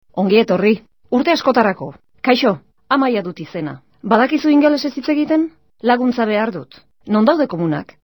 Here is a recording of a number of phrases in a mystery language.